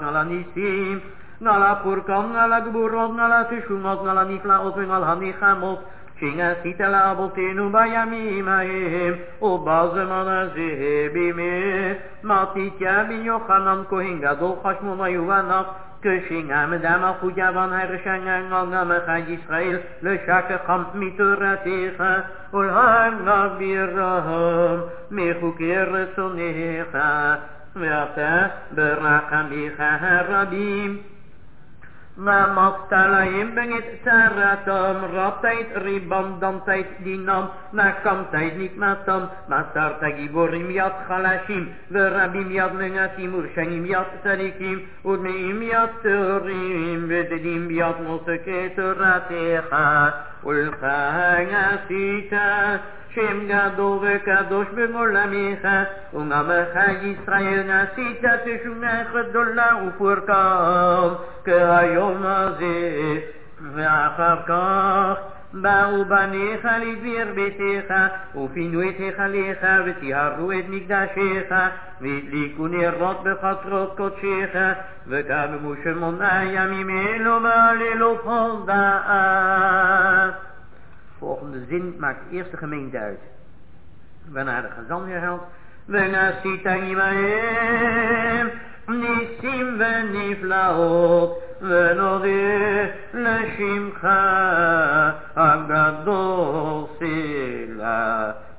Chazzan doesn't stop for congregants to say מודים דרבנן
חזן: ברכת כהנים